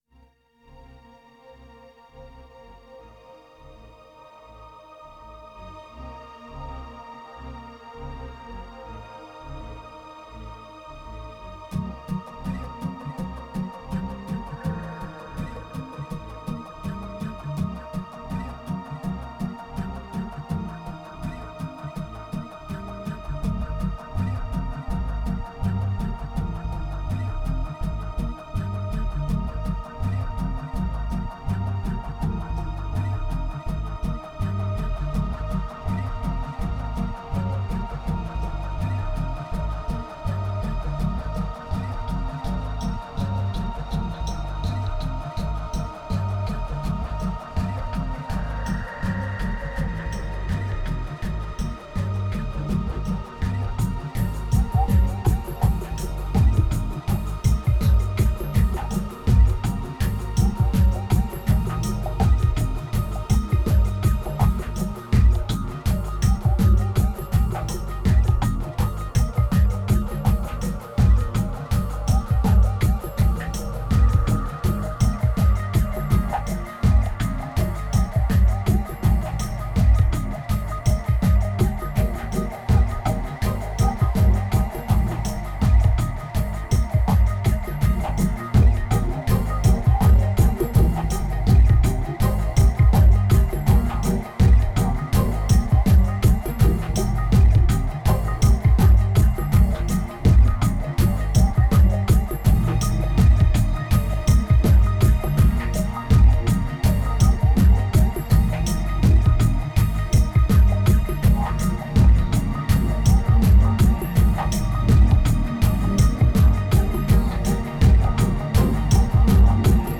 2189📈 - 23%🤔 - 82BPM🔊 - 2010-10-16📅 - -108🌟